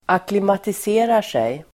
Uttal: [aklimatis'e:rar_sej]